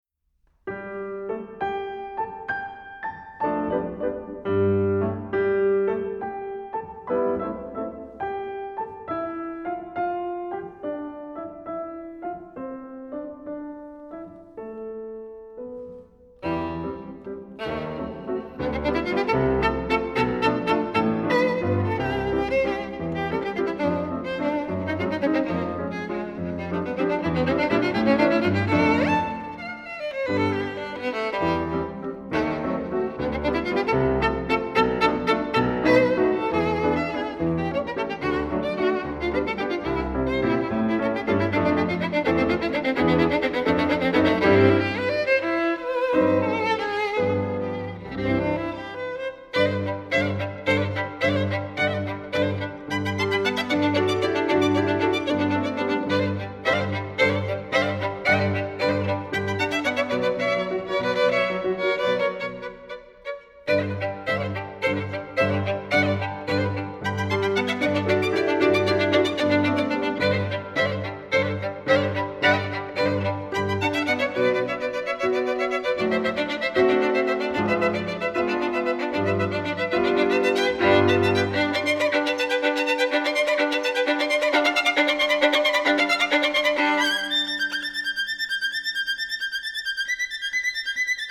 ★出身音樂世家的優秀小提琴新銳，與瑞士鋼琴家聯手合奏，絕佳默契無人能比！